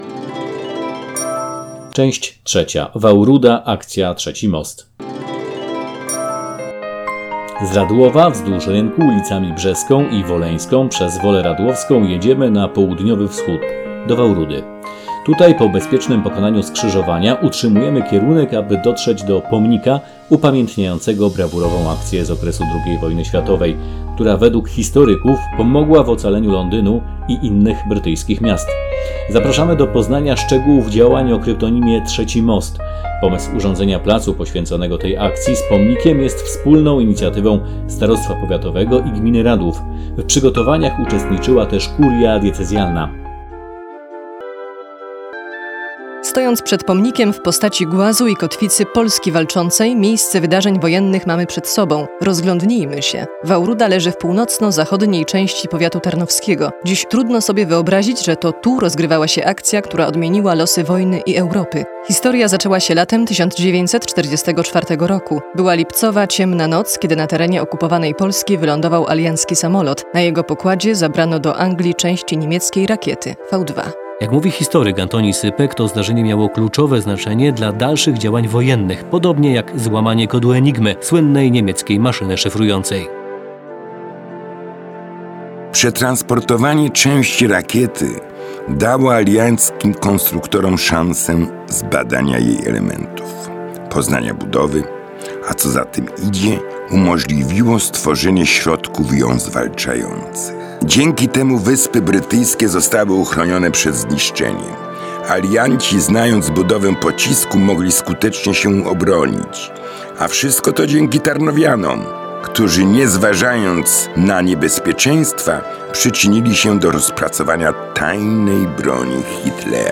Audioprzewodnik rowerowy po Ziemi Radłowskiej - rajd rowerowy
Kompetentny komentarz zaproszonych do projektu specjalistów, dysponujących najbardziej aktualnym stanem wiedzy (historycy, historycy sztuki, regionaliści) oraz wskazówki nawigacyjne pozwolą bezpiecznie przejechać wyznaczoną trasę i przyswoić sobie cenne informacje.